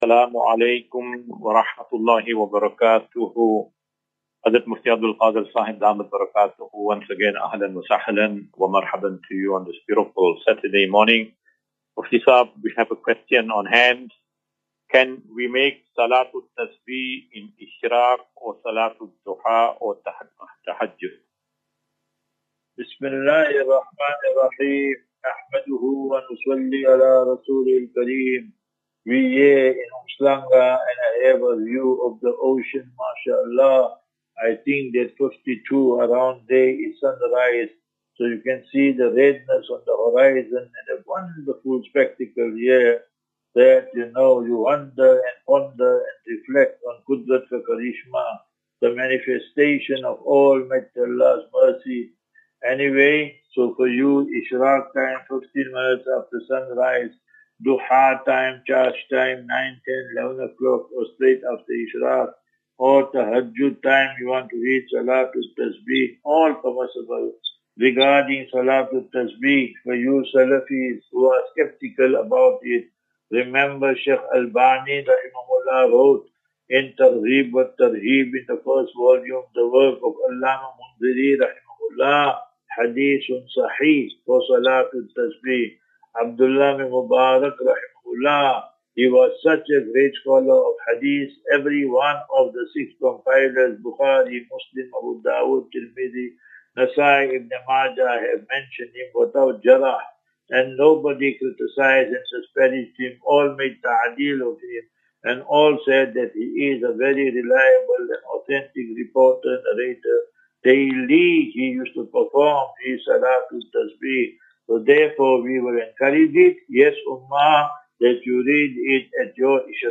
View Promo Continue Install As Safinatu Ilal Jannah Naseeha and Q and A 8 Mar 08 March 2025.